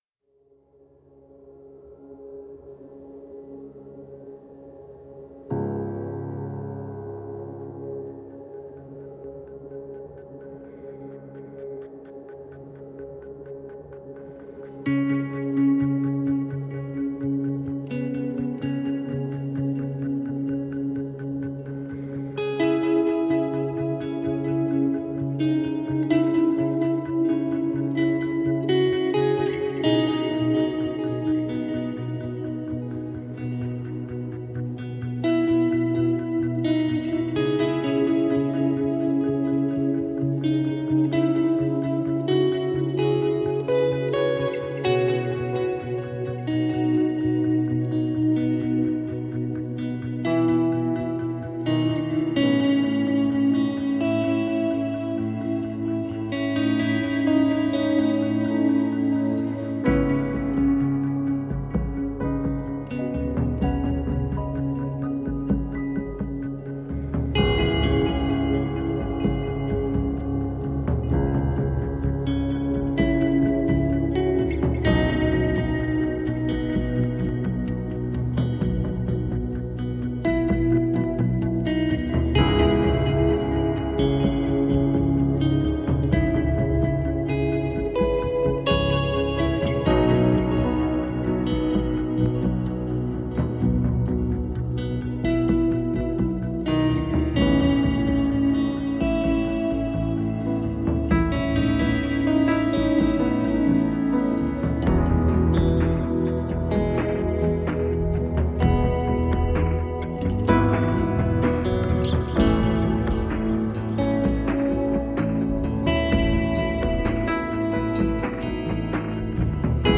Evocative, melodic and haunting instrumental guitar music.